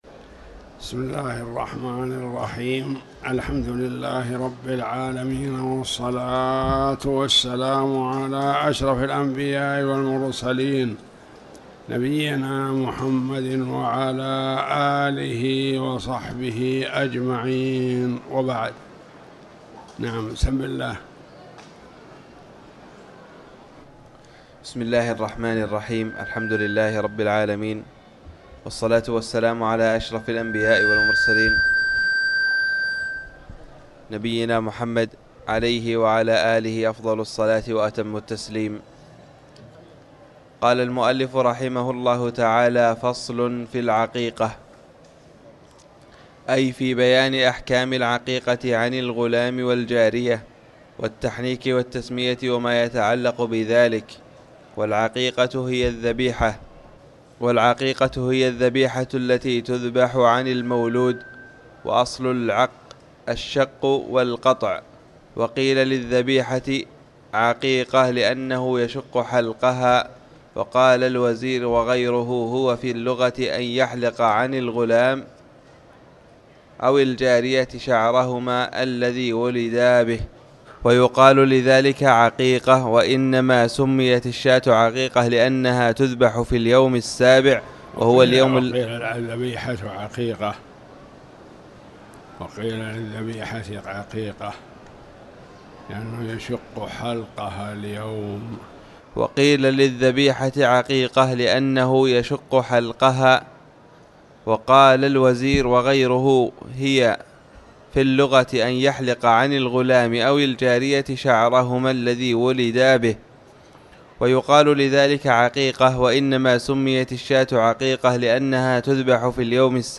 تاريخ النشر ٤ صفر ١٤٤٠ هـ المكان: المسجد الحرام الشيخ